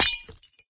glass2.ogg